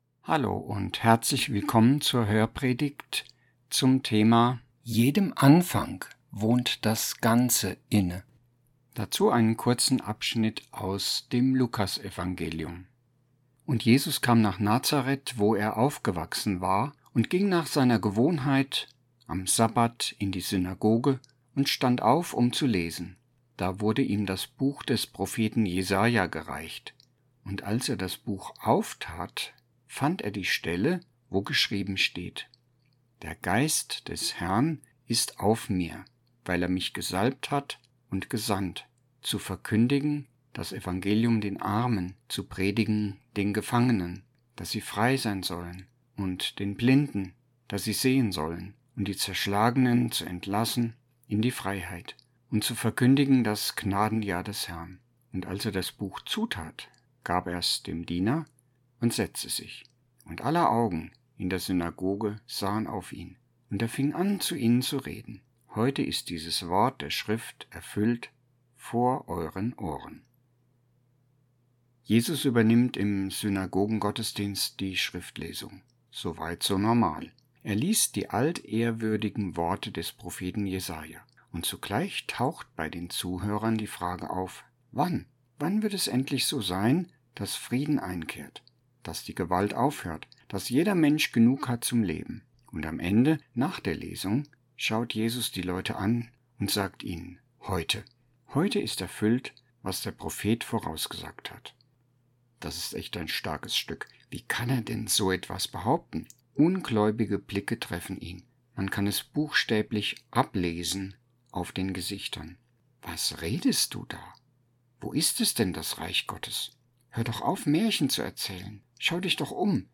Hörpredigt Mai 2025 – Außen und innen